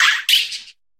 Cri de Pimito dans Pokémon HOME.